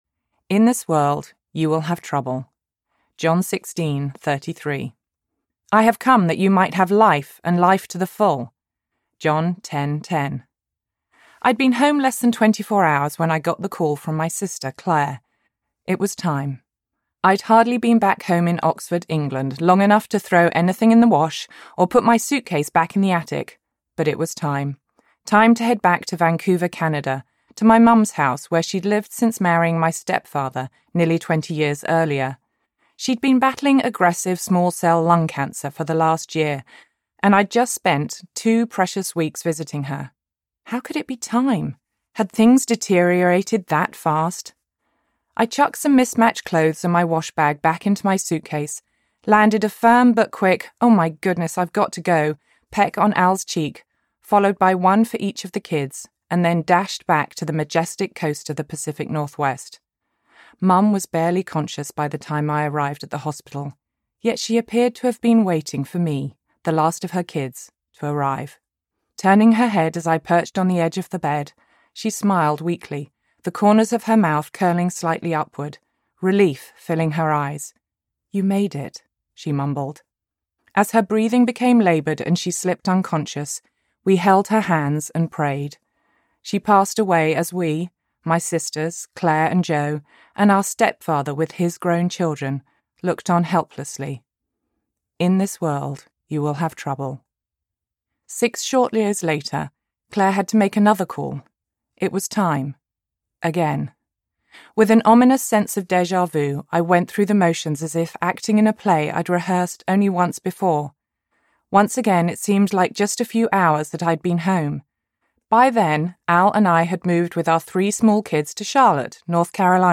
Breathe Again Audiobook
Narrator
4.9 Hrs. – Unabridged